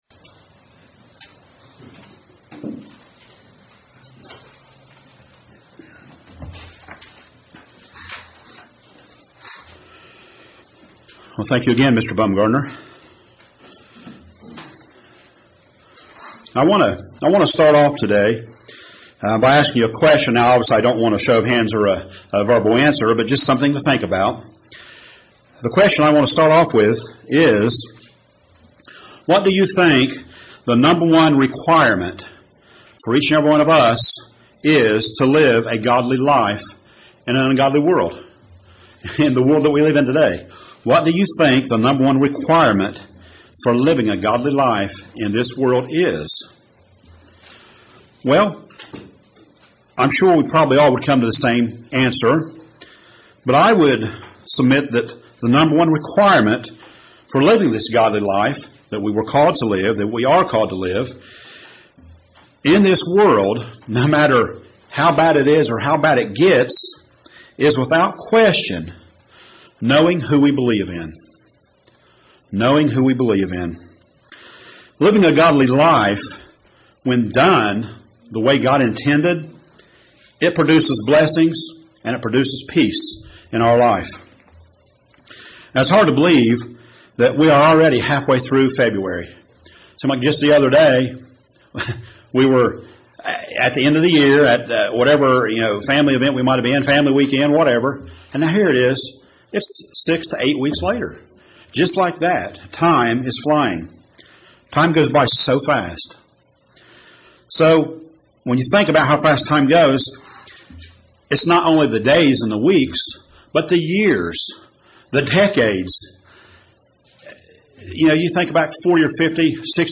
Sermons
Given in Paintsville, KY